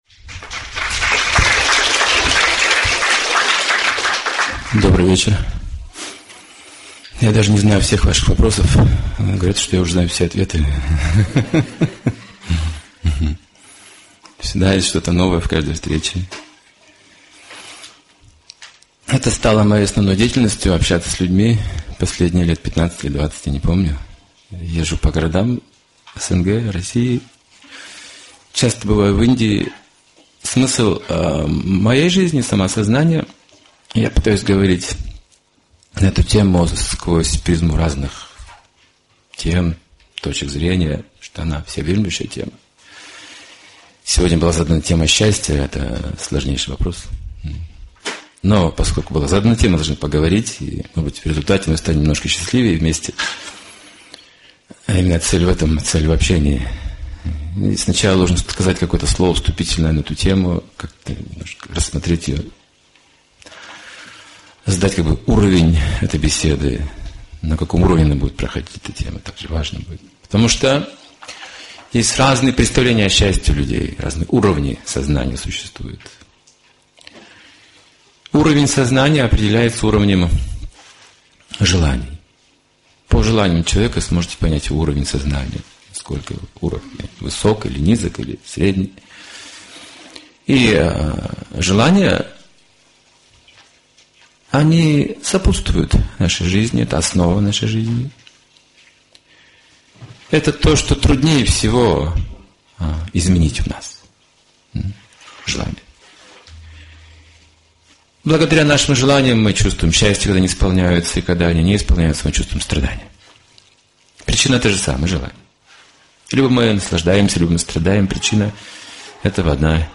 Публичная лекция о счастье (2008, Москва)